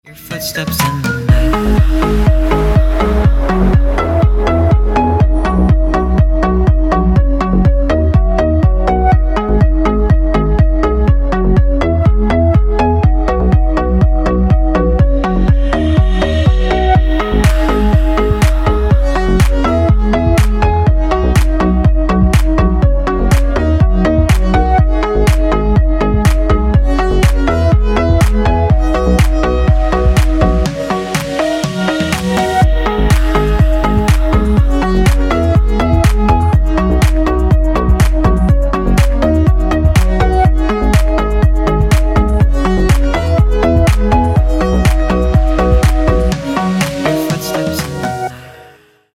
deep house
dance
Electronic
club